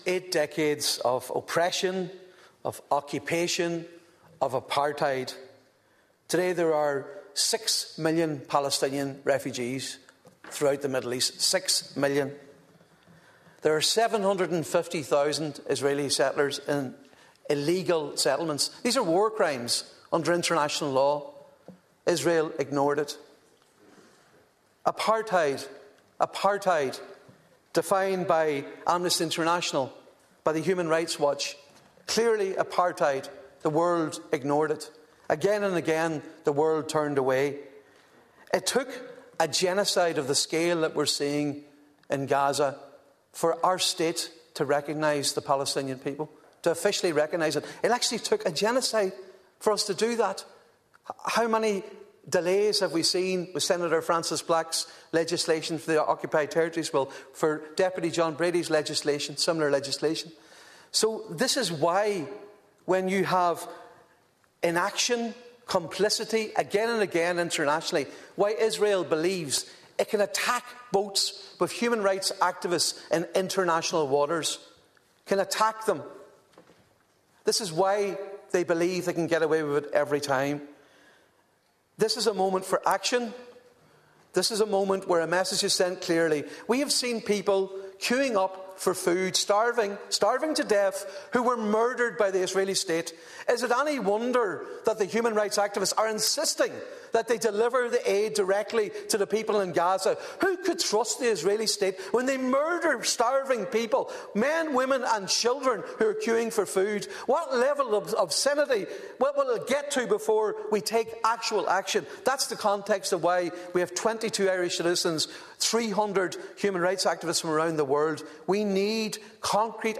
Donegal Deputy Padraig MacLochlainn spoke in the Dail on the motion: